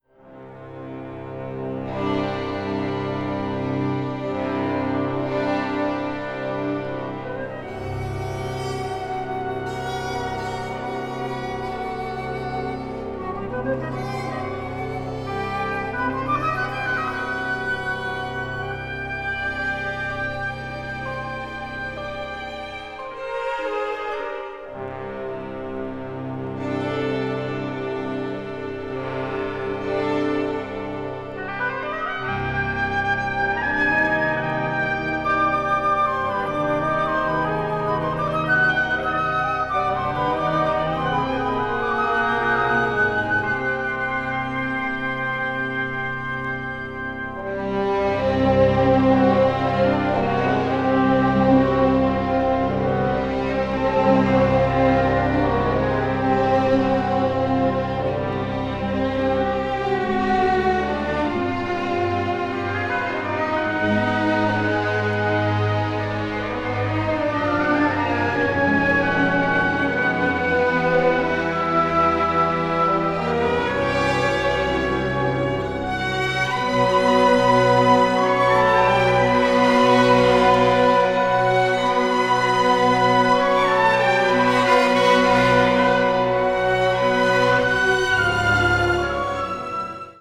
media : EX/EX(わずかにチリノイズが入る箇所あり)
中国的なモチーフがあしらわれながら大陸的なスケール感に彩られた、ミステリアスでドラマティックな世界を描き出しています。
シンセサイザーのような音も聞こえてきます。